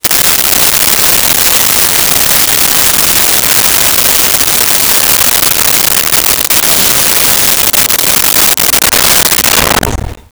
Shower Off
Shower Off.wav